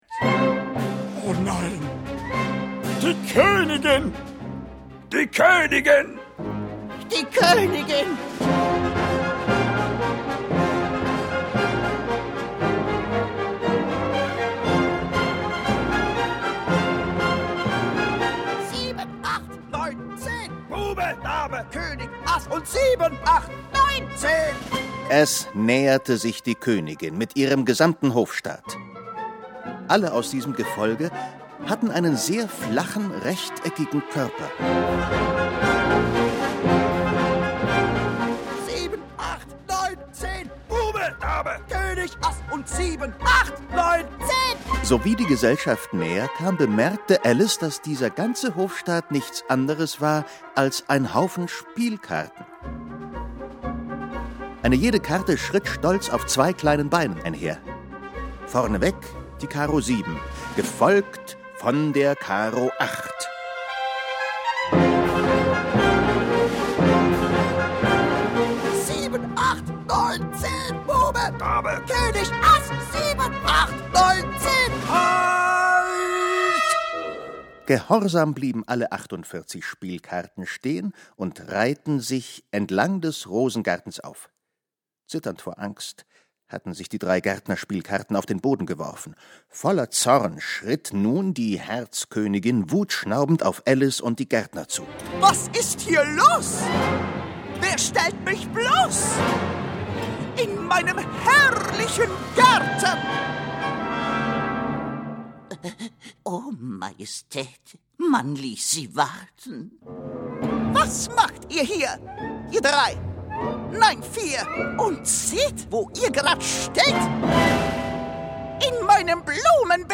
Orchesterhörspiel
Eine Wunderwelt dargestellt von dem mächtigen Orchesterklang